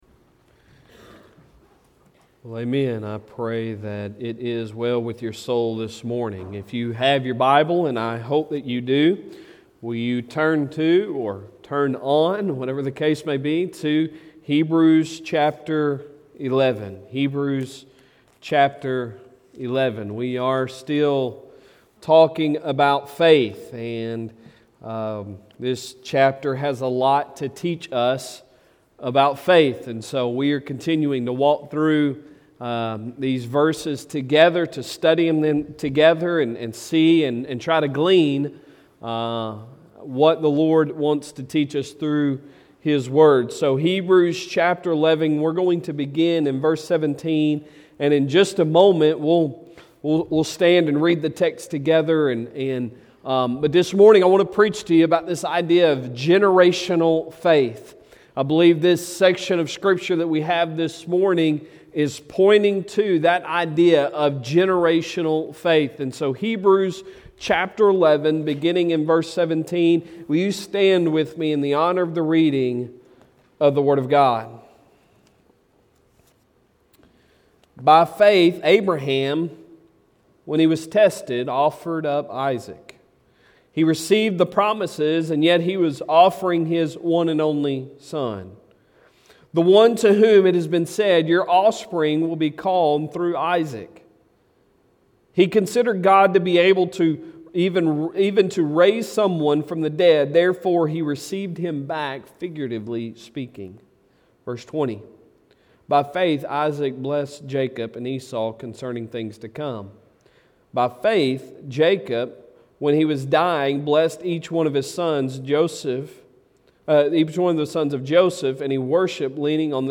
Sunday Sermon January 23, 2022